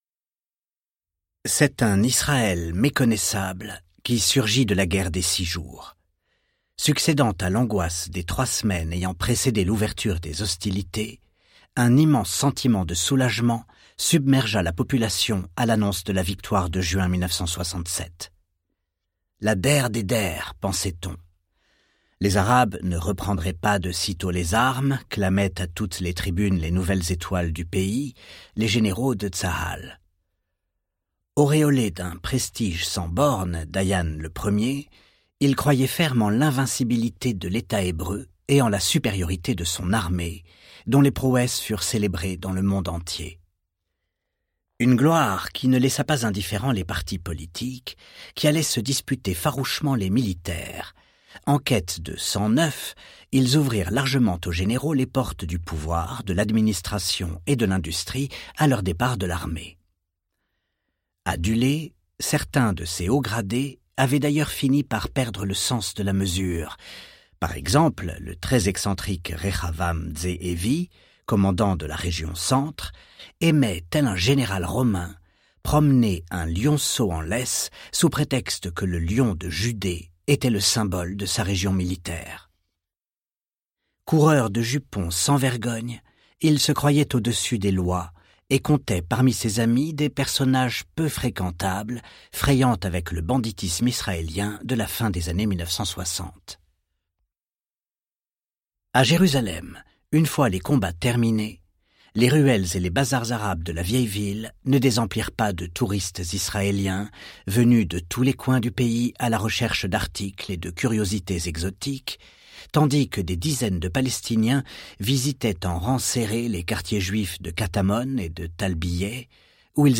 Livre audio Histoire d' Israël Tome2. de Michel Abitbol | Sixtrid
Texte : Intégral